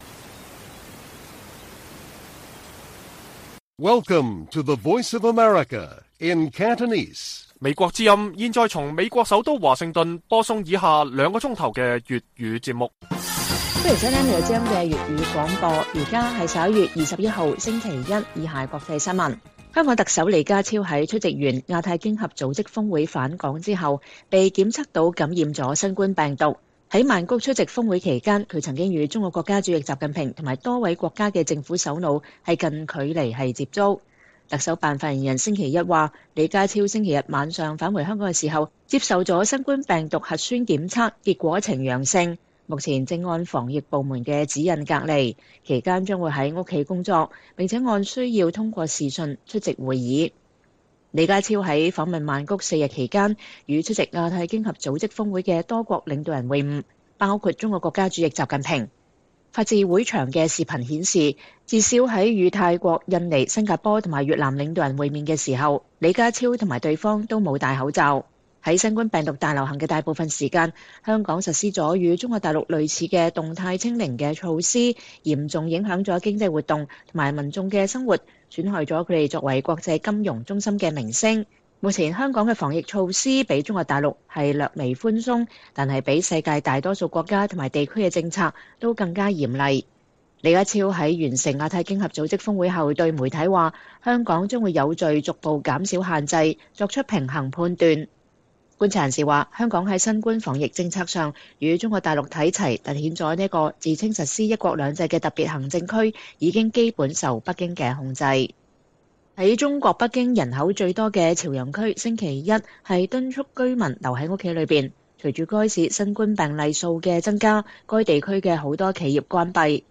粵語新聞 晚上9-10點: 香港特首APEC峰會結束後新冠檢測呈陽性